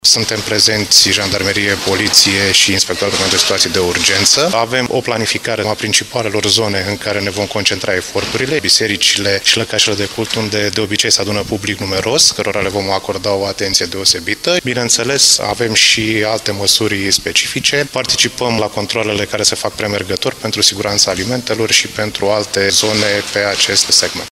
Totodată, un număr de 150 de jandarmi vor participa zilnic la activități de menținere a ordinii publice, arată col. Cristian Păltinișan, șeful Inspectoratului de Jandarmi al Județului Brașov: